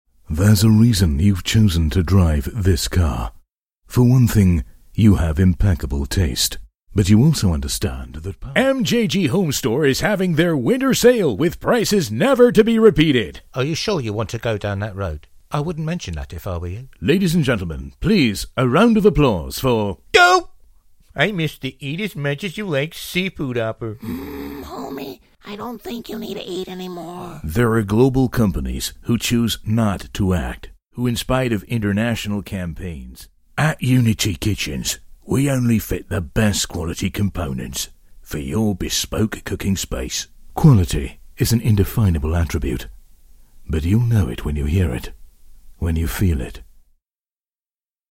Male
English (British)
Adult (30-50)
Rich modulated, medium age range, warm authorititave to lively sales. Can do all major styles of required recorded voice in either mid USA accent or neutral British.
VOICE ACTOR DEMOS